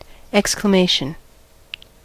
Ääntäminen
Ääntäminen US : IPA : [ˌɛk.sklə.ˈmeɪ.ʃən]